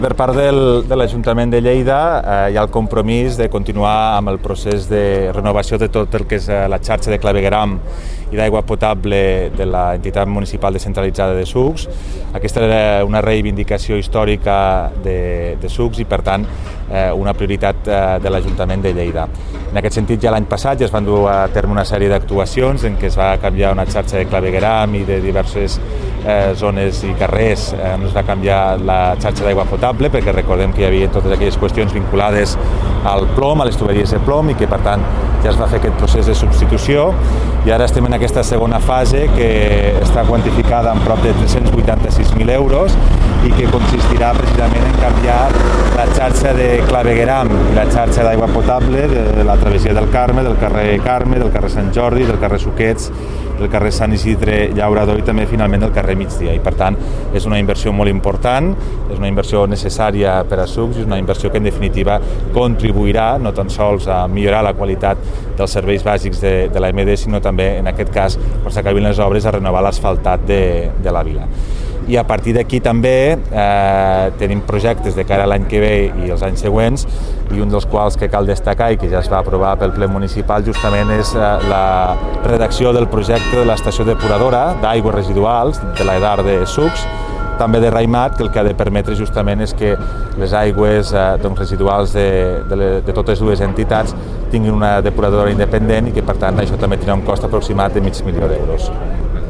Tall de veu de Toni Postius